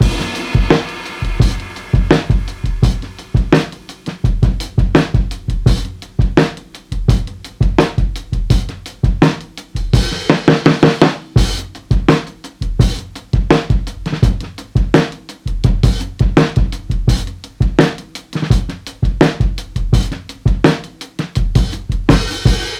Drive Thru Break.wav